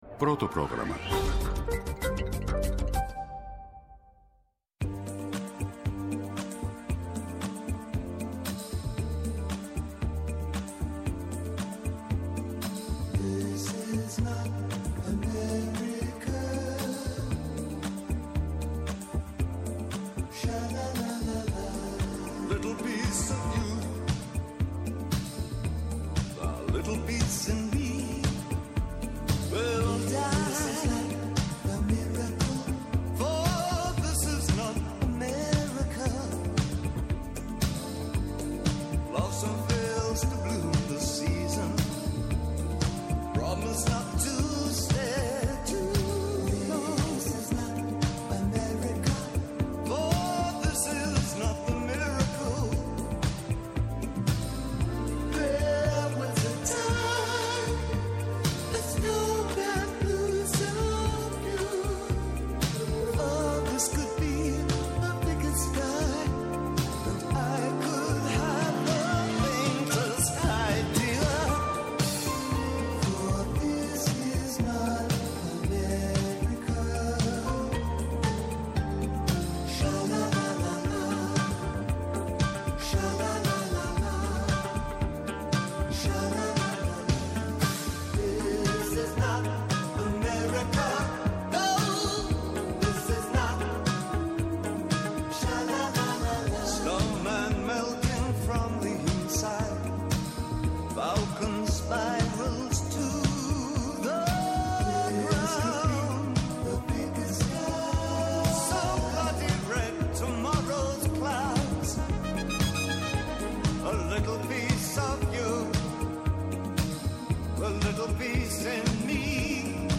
-Και η Αλεξάνδρα Σδούκου, υφυπουργός περιβάλλοντος.